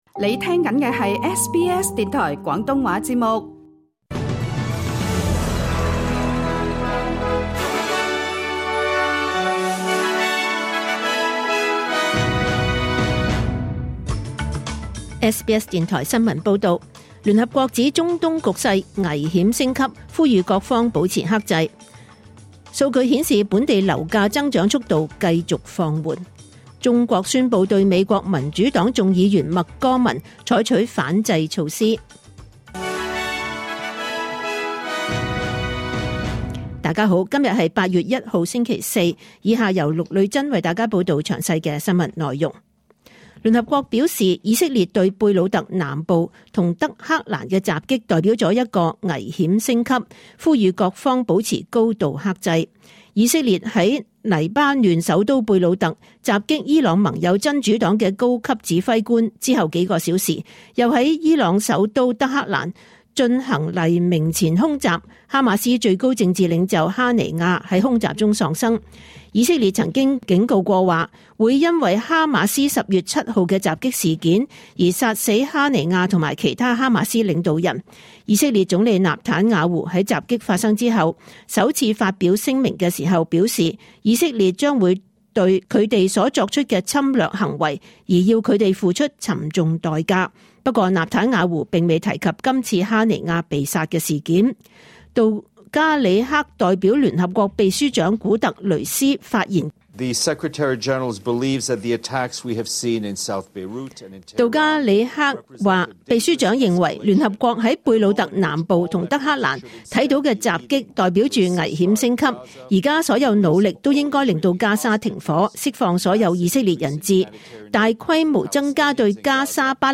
SBS廣東話新聞報道